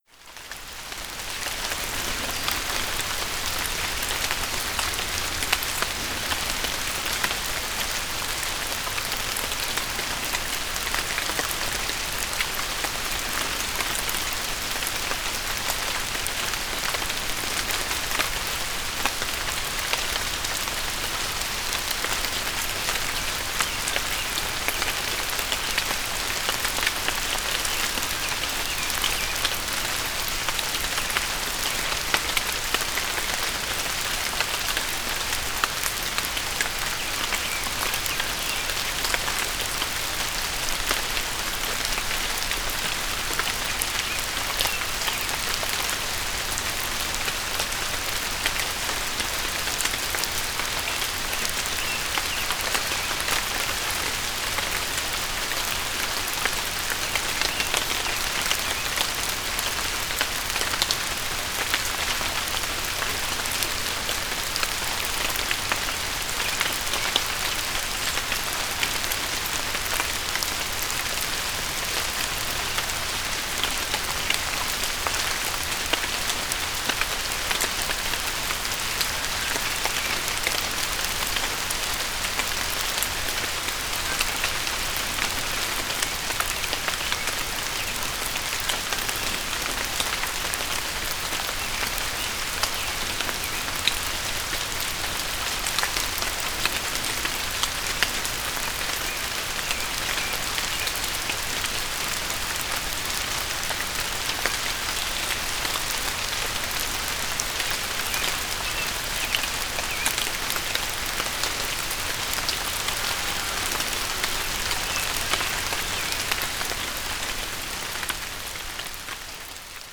Pluie apaisante : sons de pluie relaxants pour mieux dormir, méditer et calmer le stress